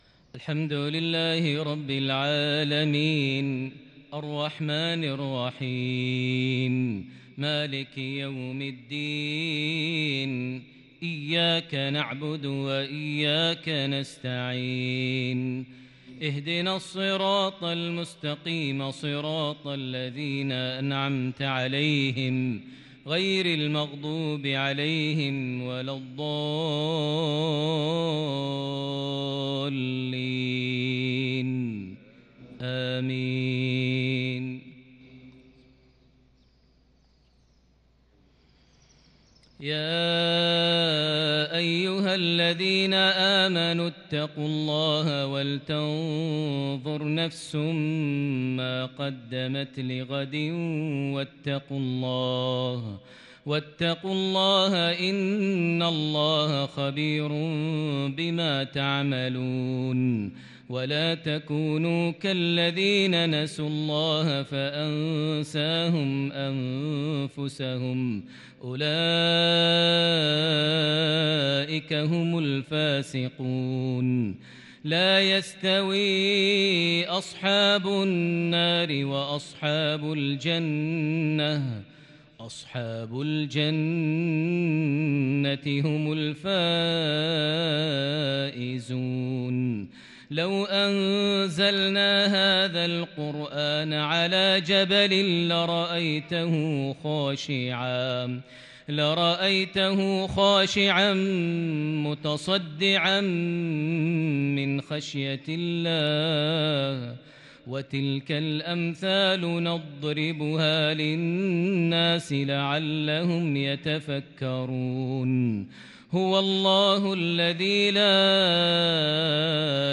انتقاءات متفردة بتحبير الكرد لخواتيم سورتي الحشر + المنافقون |مغرب 22 ذي الحجة 1442هـ > 1442 هـ > الفروض - تلاوات ماهر المعيقلي